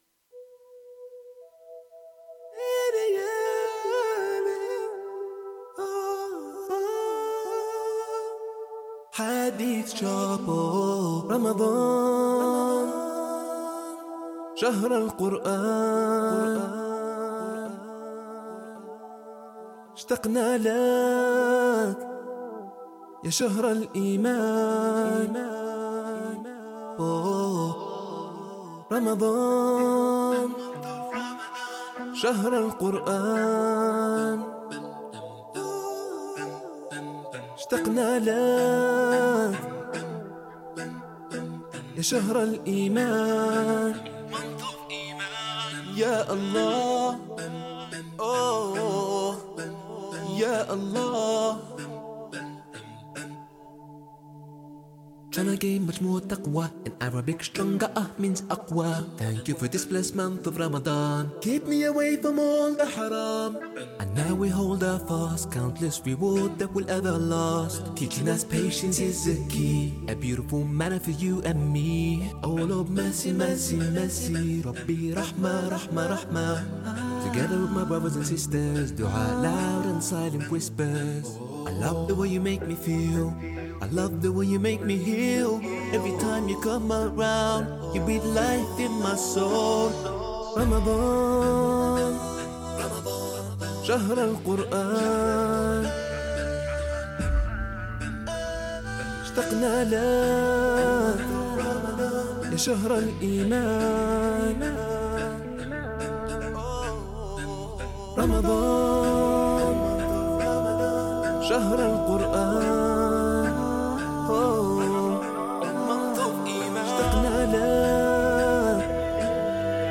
Nashid